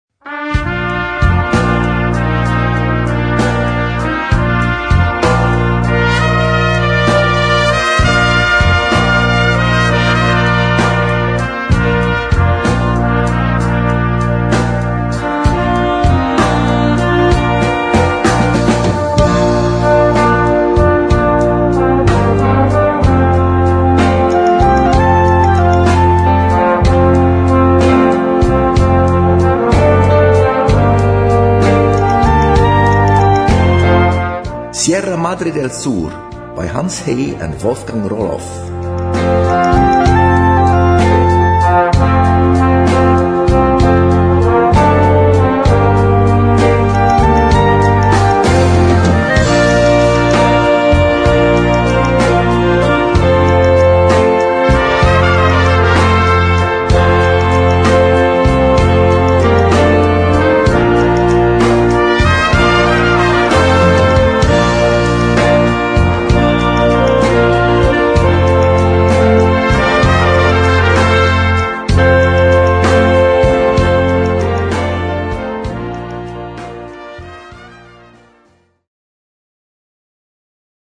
Adapt. für Brass Band
Noten für Blasorchester, oder Brass Band.